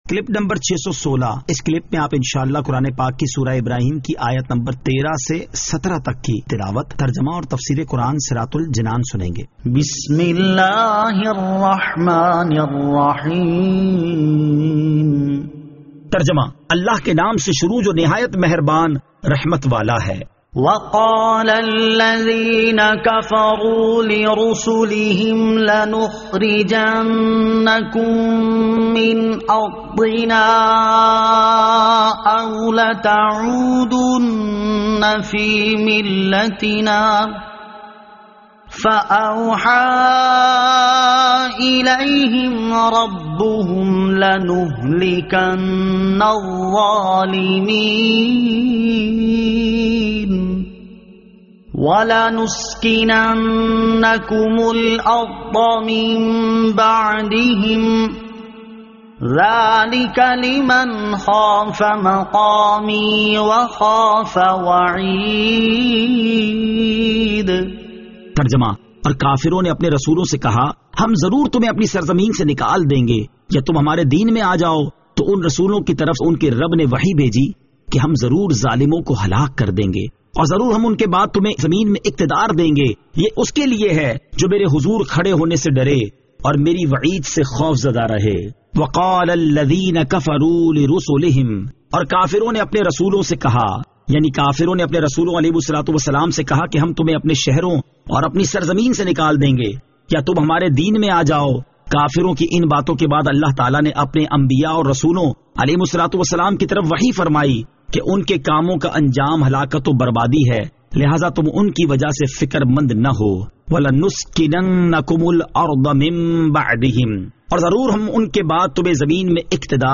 Surah Ibrahim Ayat 13 To 17 Tilawat , Tarjama , Tafseer